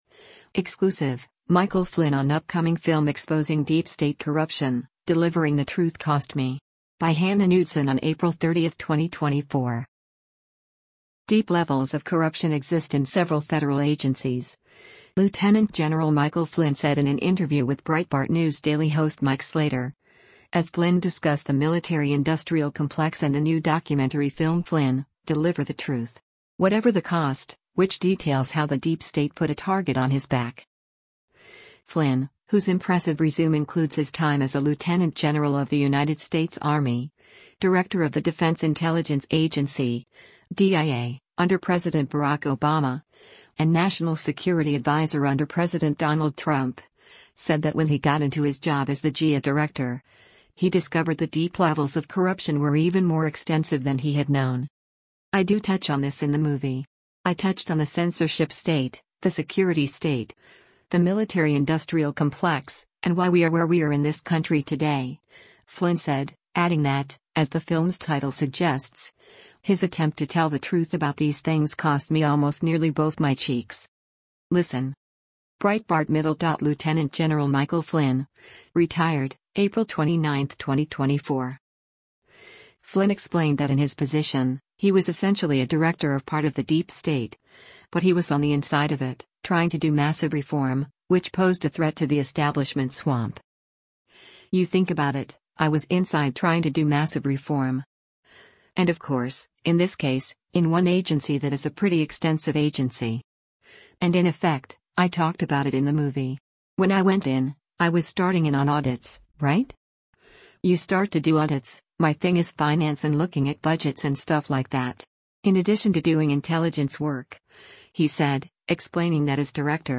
Exclusive -- Michael Flynn on Upcoming Film Exposing Deep State Corruption